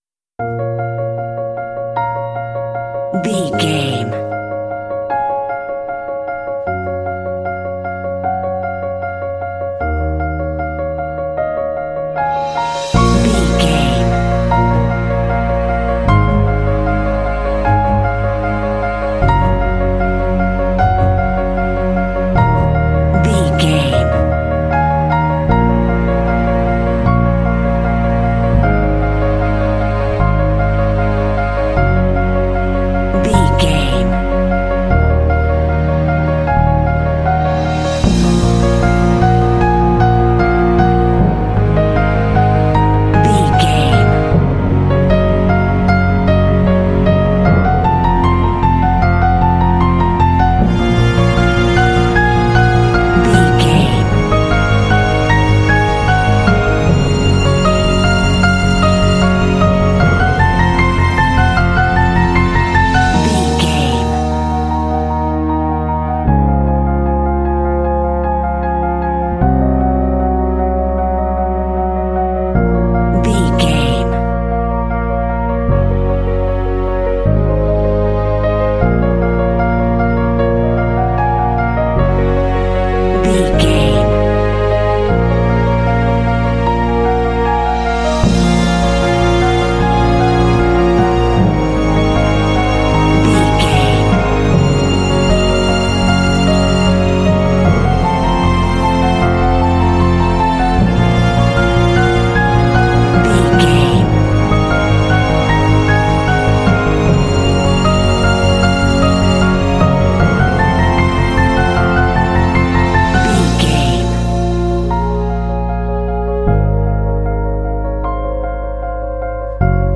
Epic / Action
Fast paced
Aeolian/Minor
B♭
piano
strings
cinematic
classical
underscore